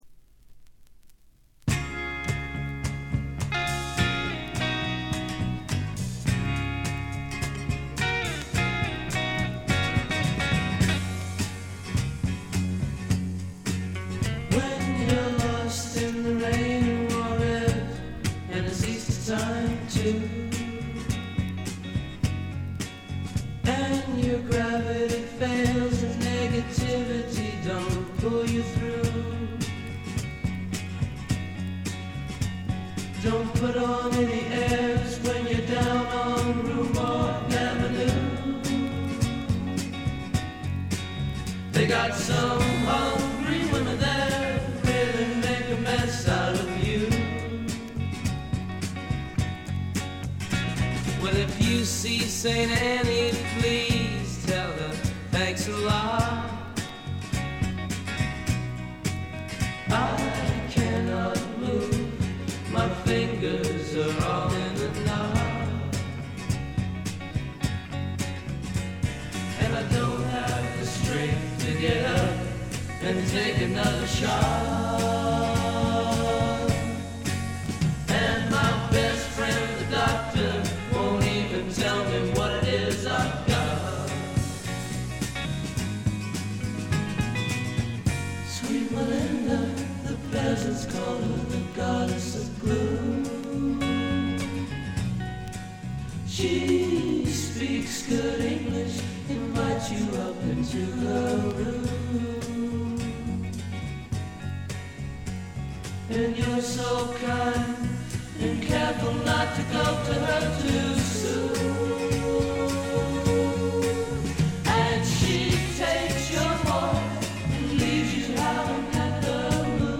軽微なチリプチ程度。
試聴曲は現品からの取り込み音源です。